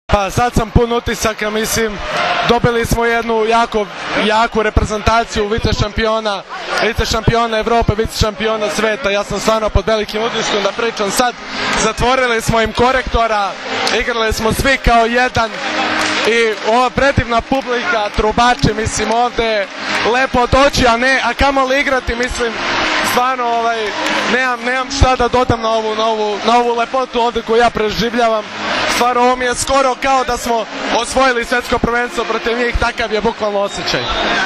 IZJAVA UROŠA KOVAČEVIĆA, KAPITENA SRBIJE